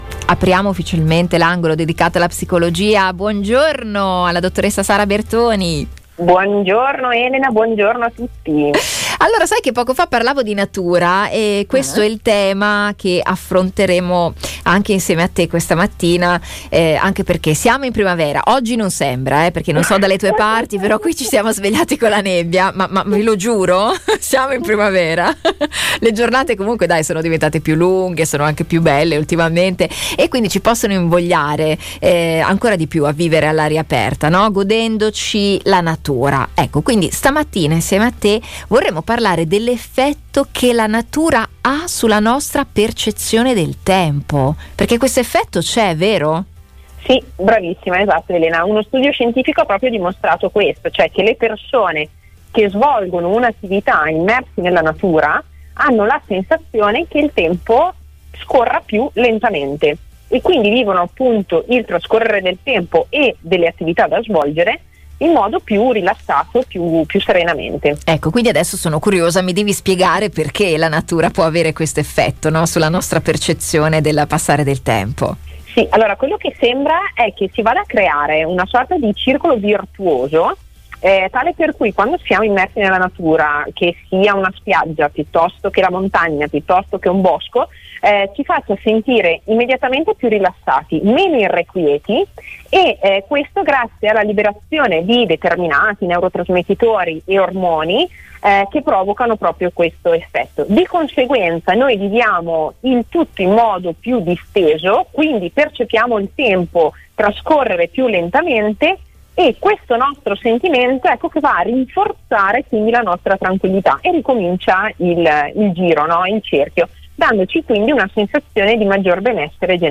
Ecco l’intervento completo della nostra esperta: